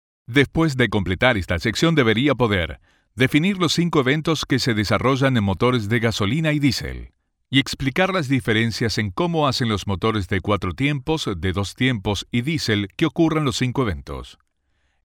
Adult male voice, warm and trustworthy, with clear diction and strong on-mic presence.
E-Learning